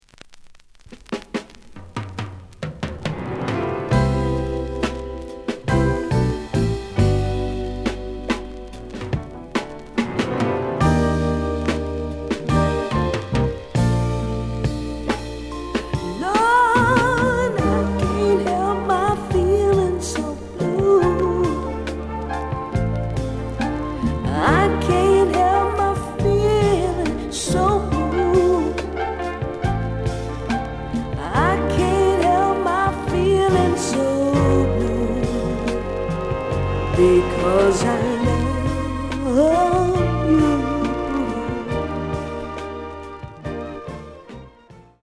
The audio sample is recorded from the actual item.
●Format: 7 inch
●Genre: Soul, 70's Soul